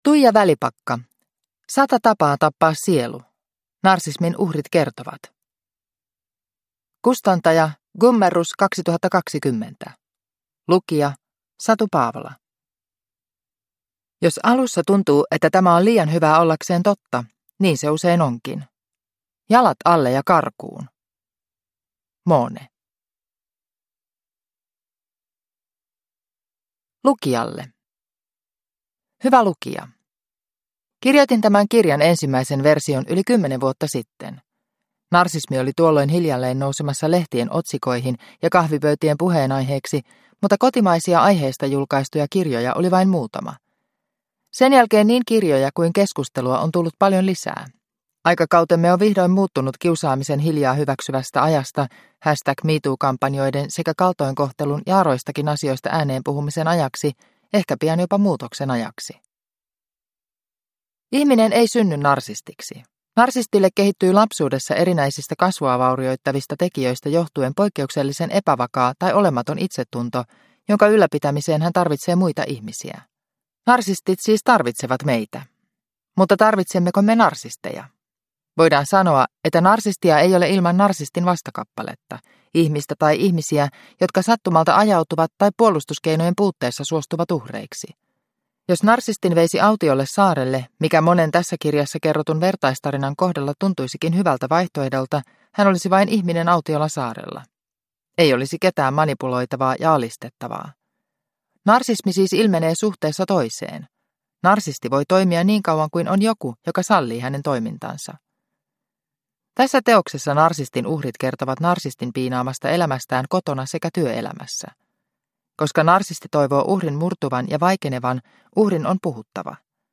Sata tapaa tappaa sielu – Ljudbok